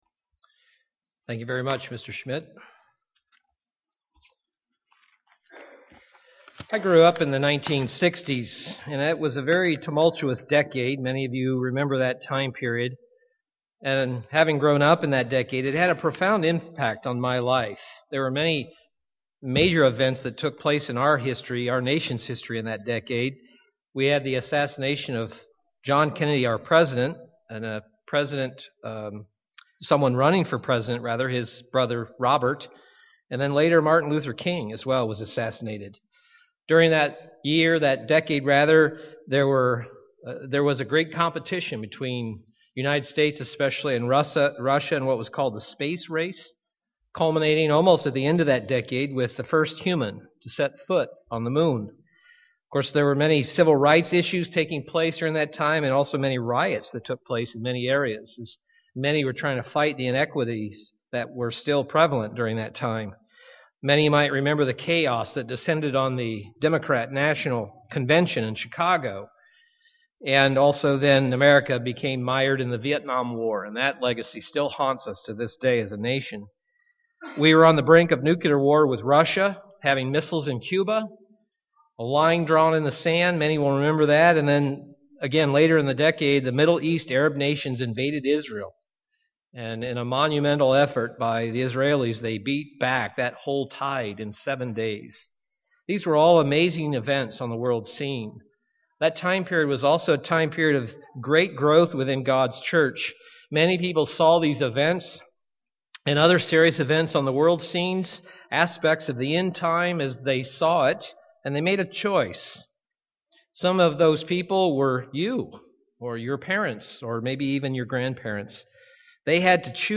God's way or this world's UCG Sermon Studying the bible?